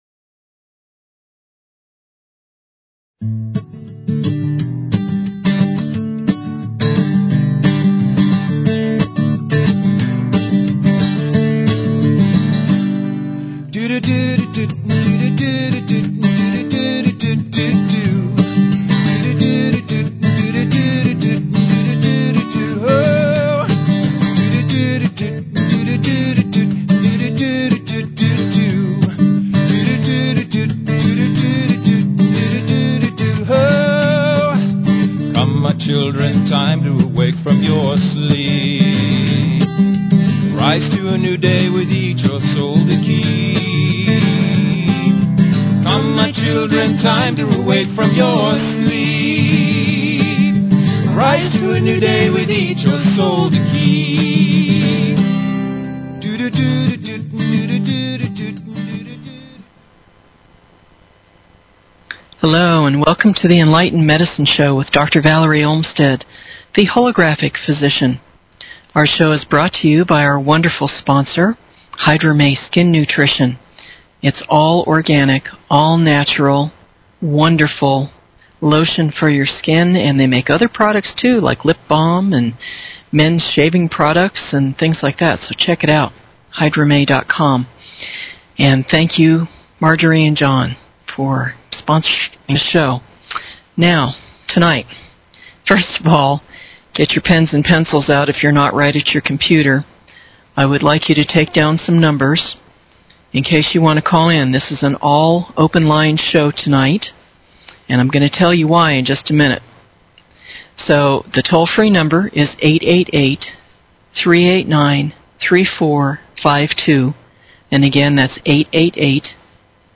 Talk Show Episode, Audio Podcast, Enlightened_Medicine and Courtesy of BBS Radio on , show guests , about , categorized as
Open lines--call in and voice your opinion.